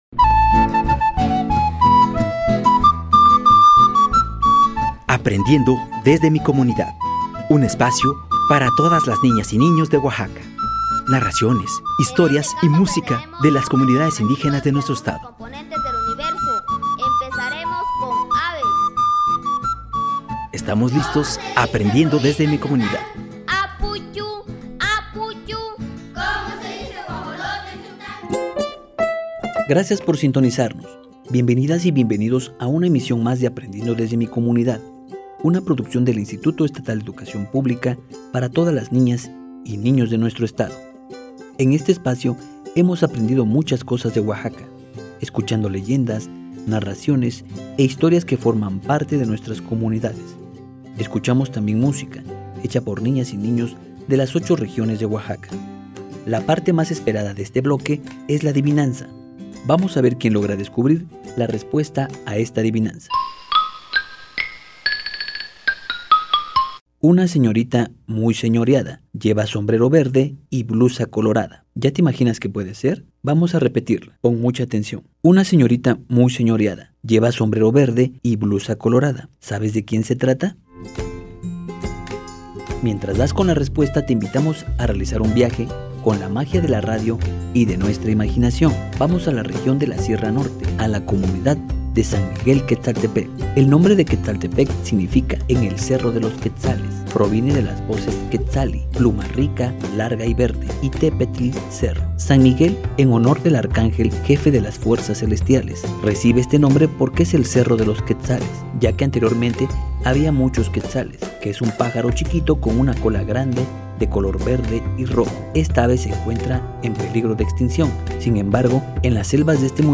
Mixe medio del este de San Miguel Quetzaltepec
mixe-medio-del-este-de-san-miguel-quetzaltepec.mp3